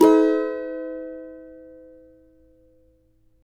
CAVA A#MN  D.wav